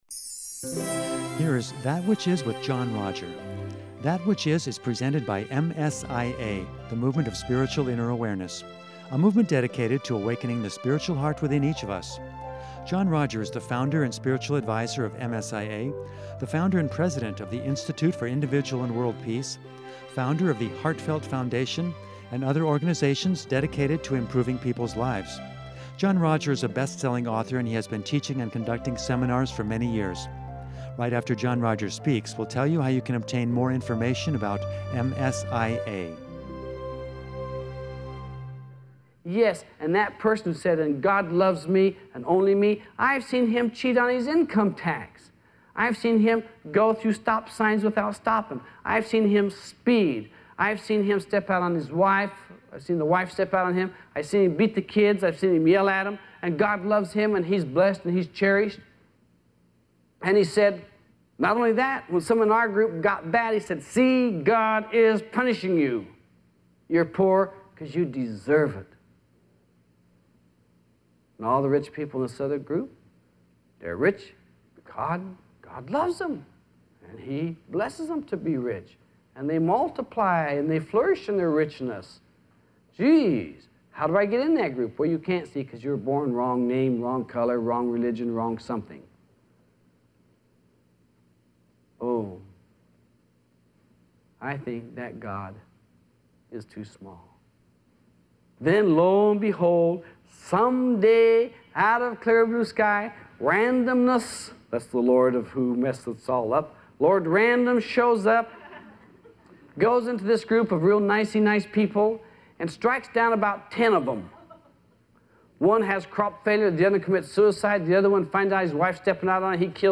In the second part of this wonderful seminar